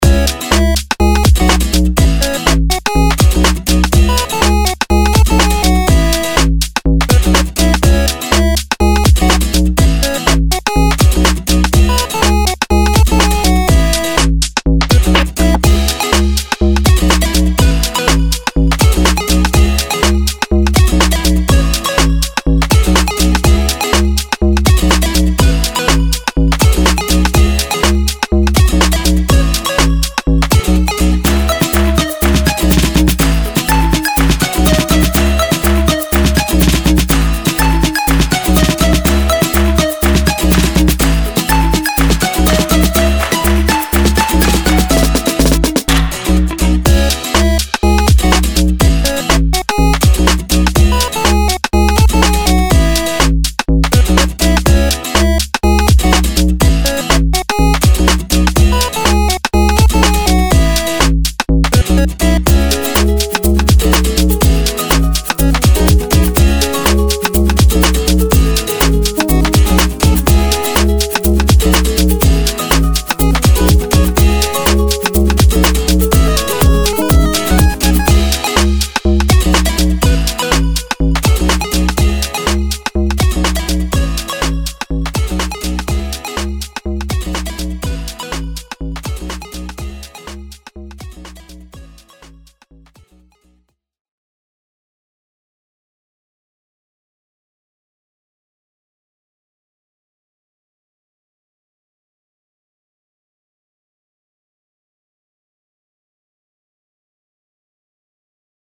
おしゃれ かっこいい FREE BGM